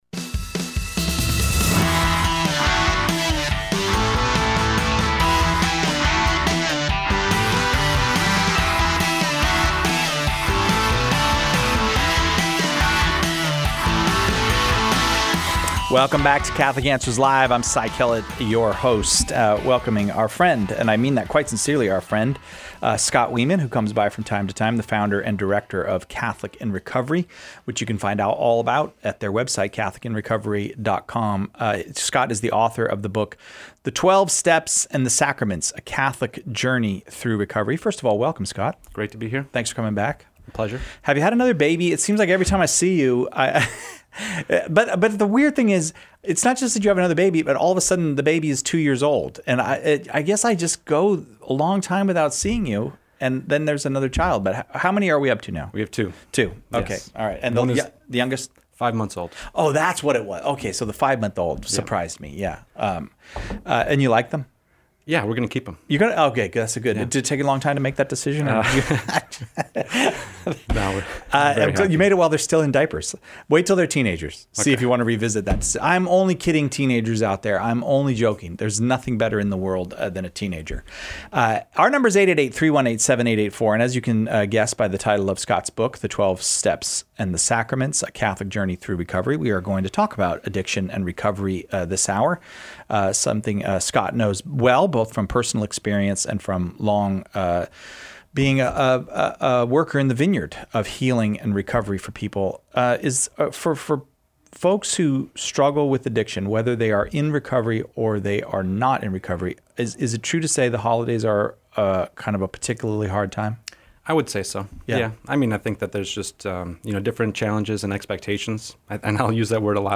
joins us to help callers navigate Questions